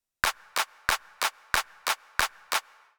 14 Clapping and Counting Basic Rhythms
Eighth notes.